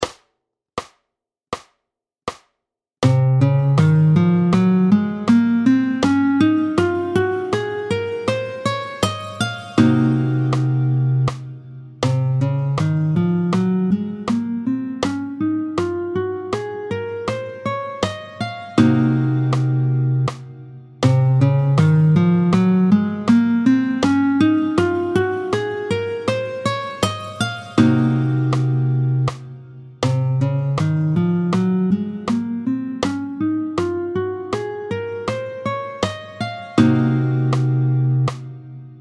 Le mode (et le doigté VII) : do réb mib fa solb lab sib do, est appelé le Locrien
27-07 Le doigté du mode de Do locrien, tempo 80
27-07-Do-locrien.mp3